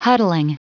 Prononciation du mot huddling en anglais (fichier audio)
huddling.wav